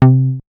MoogBigUp B.WAV